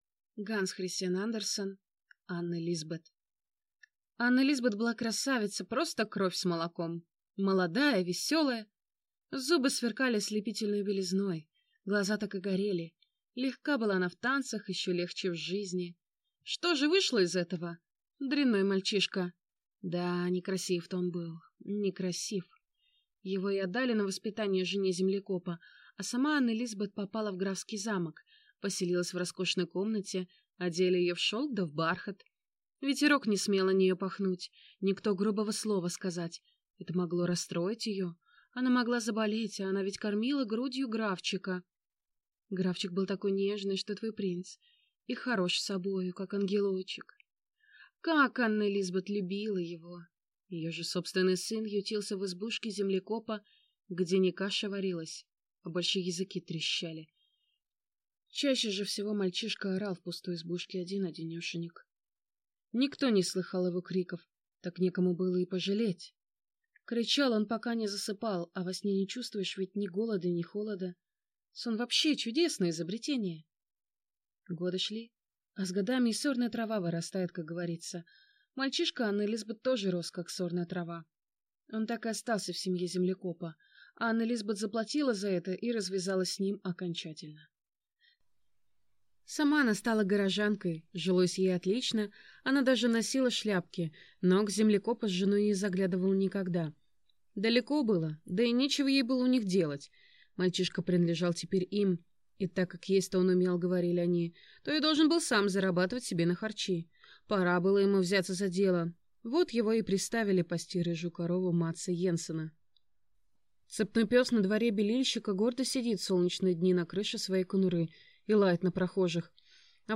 Аудиокнига Анне Лисбет | Библиотека аудиокниг